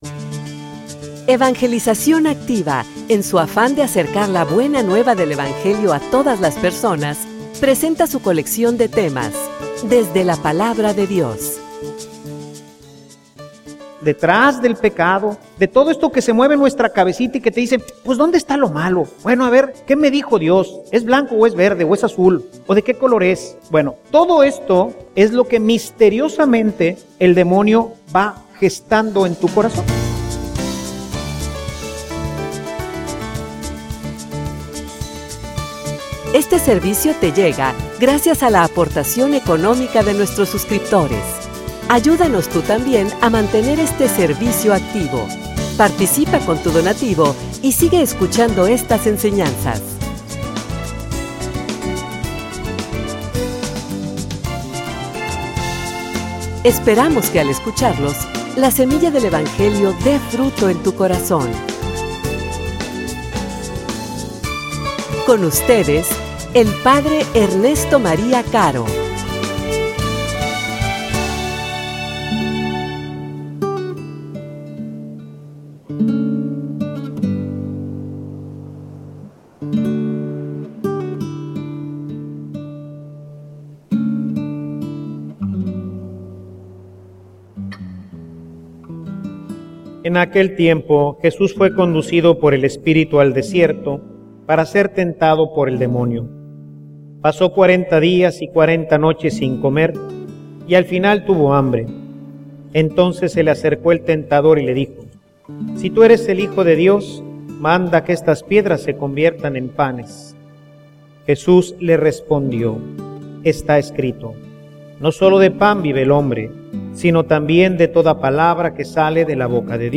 homilia_Que_no_te_engane.mp3